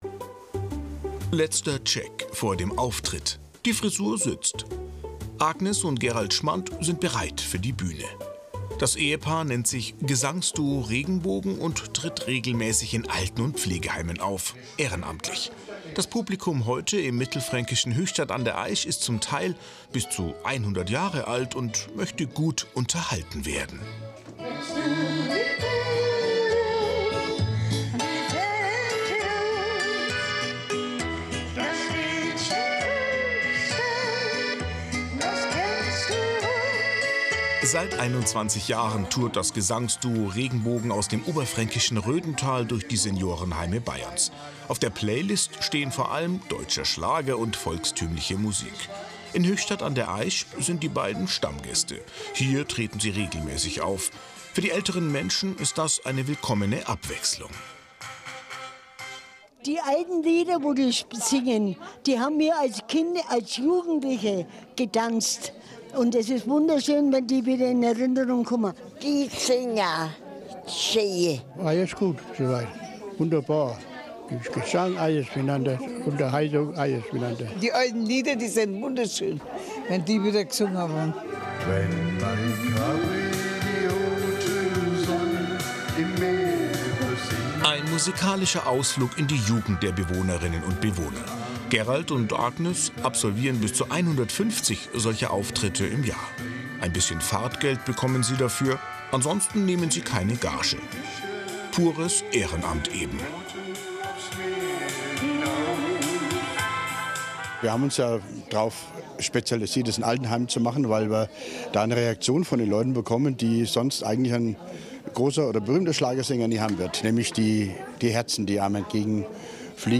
Auftritt Frankenschau 19.9.24.mp4
Auftritt+Frankenschau+19.9.24.mp4